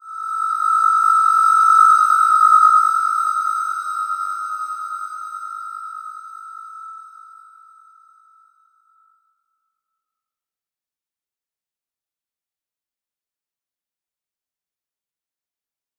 Slow-Distant-Chime-E6-f.wav